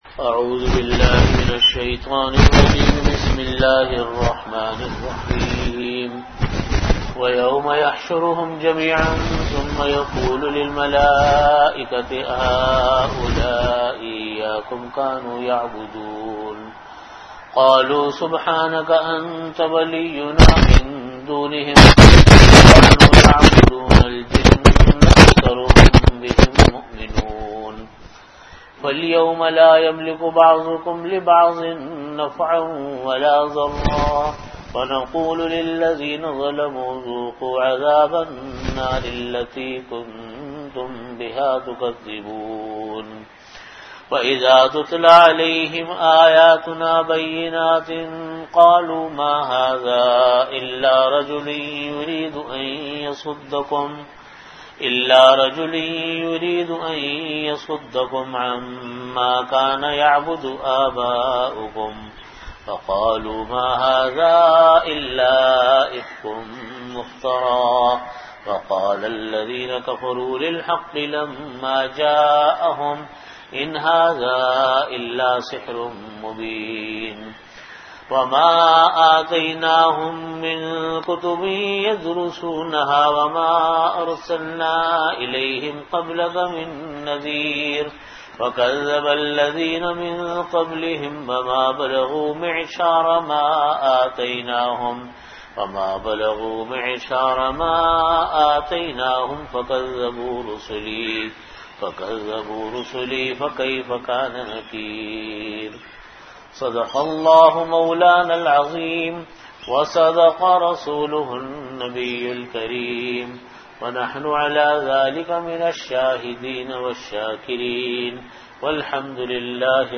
Category: Tafseer
Time: After Asar Prayer Venue: Jamia Masjid Bait-ul-Mukkaram, Karachi